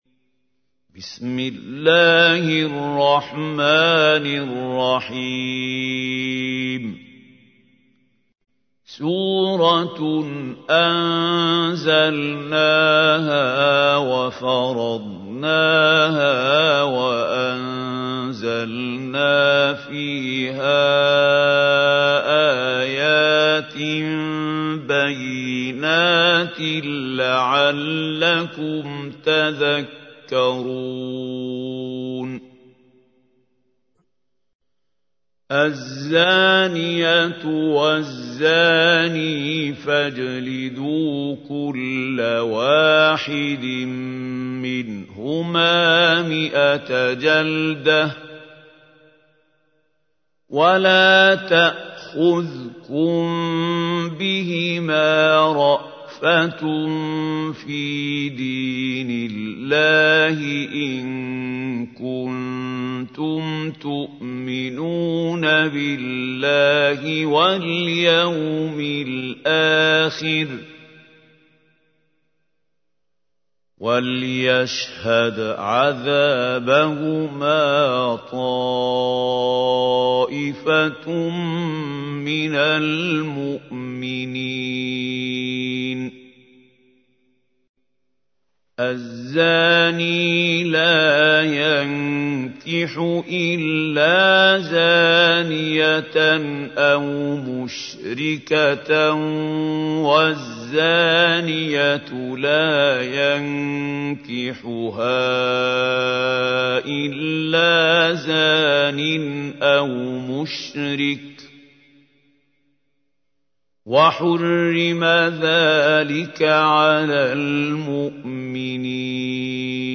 تحميل : 24. سورة النور / القارئ محمود خليل الحصري / القرآن الكريم / موقع يا حسين